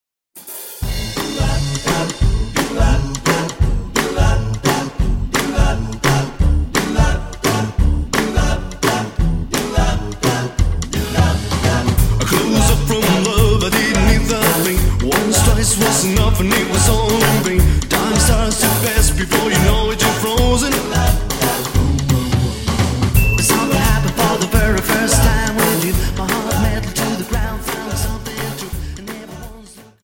Dance: Jive